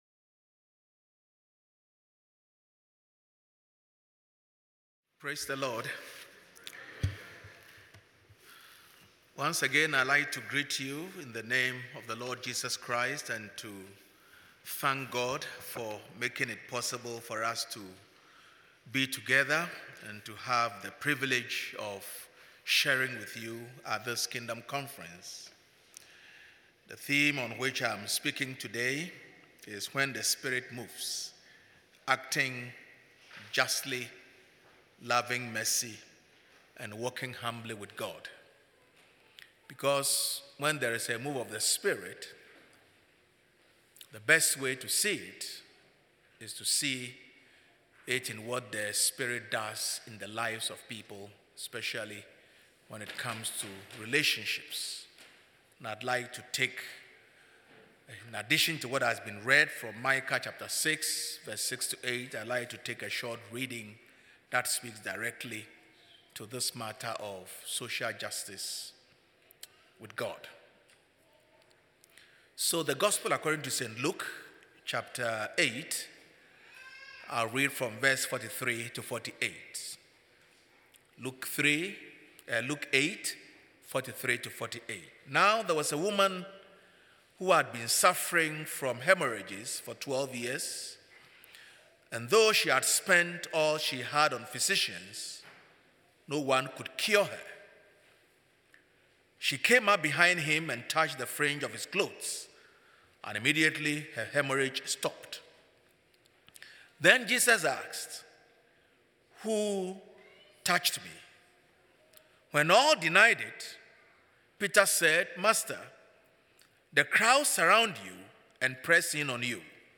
presented by Asbury Seminary Kentucky Chapel, recorded on Thursday, April 11, 2024 at Asbury Theological Seminary's Kentucky campus